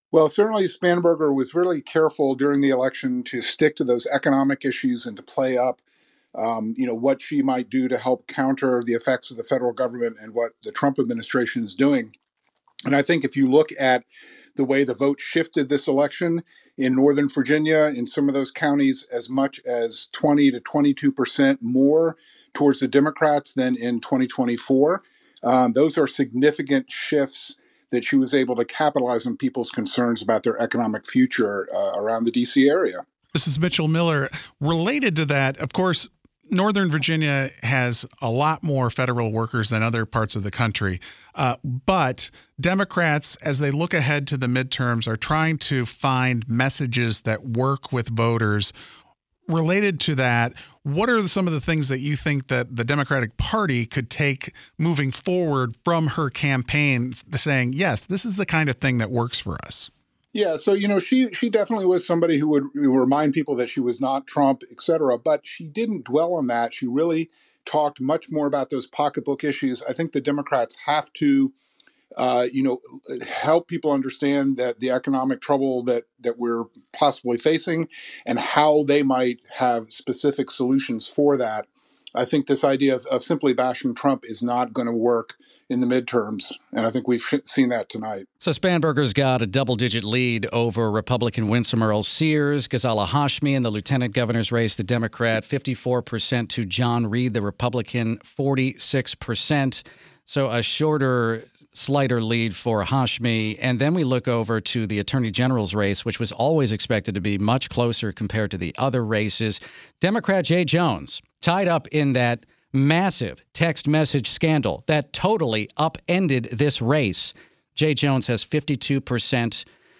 joined WTOP to explain what gave Abigail Spanberger her historic win in Virginia.